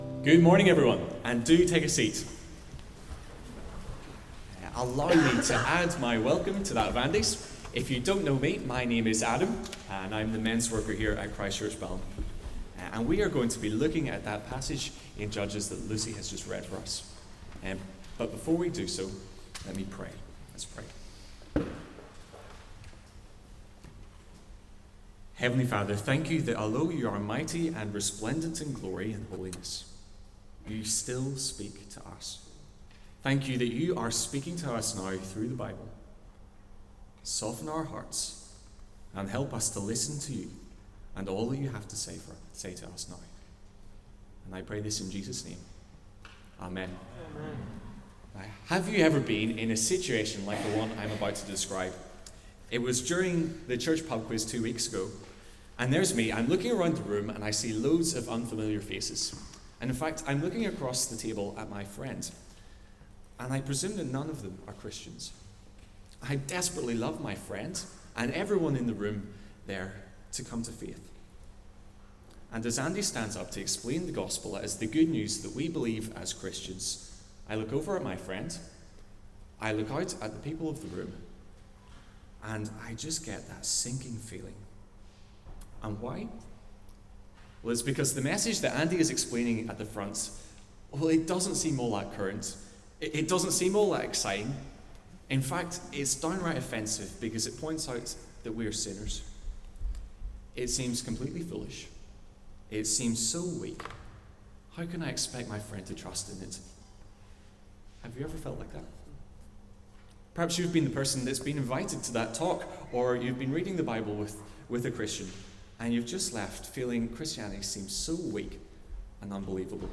The second sermon on our series looking at the life of Gideon.